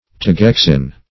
Search Result for " teguexin" : The Collaborative International Dictionary of English v.0.48: Teguexin \Te*guex"in\, n. (Zool.) A large South American lizard ( Tejus teguexin ).